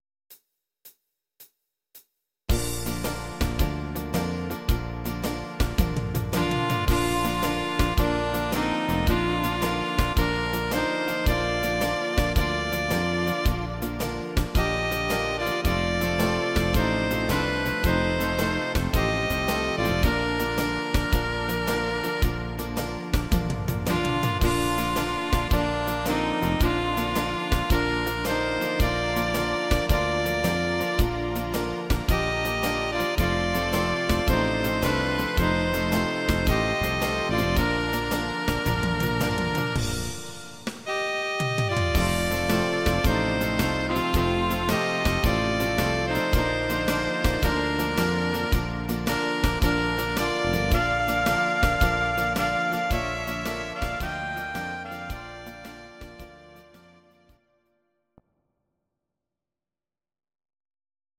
Audio Recordings based on Midi-files
instr. Saxophon